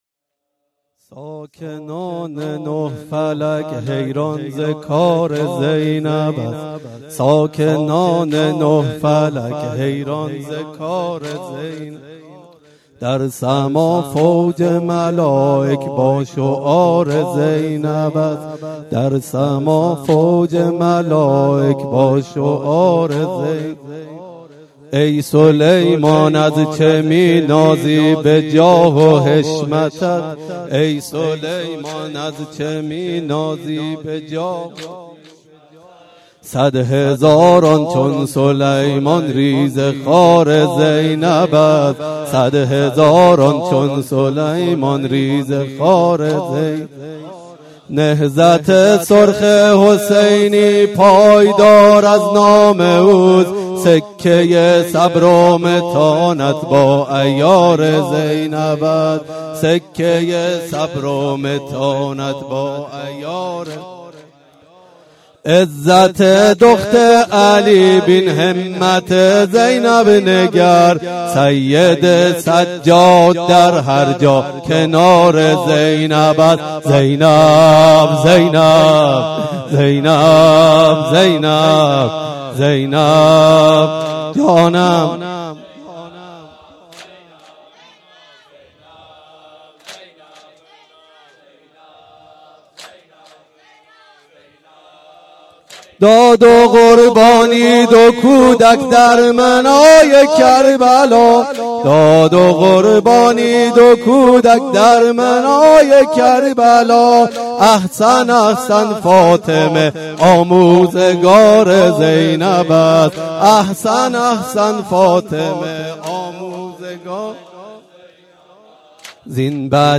شب شهادت حضرت زینب سلام الله علیها 1396
شهادت-حضرت-زینب-س-96-واحد2.mp3